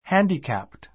hǽndikæpt